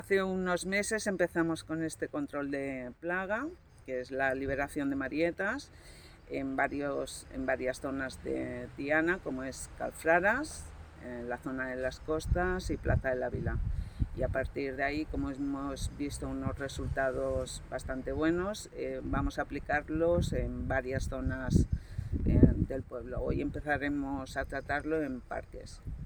Aquest divendres 28 de juliol ha sigut el torn del parc de l’antic camp de futbol. Ho explica la regidora d’Urbanisme sostenible i Espai públic, Núria Amado: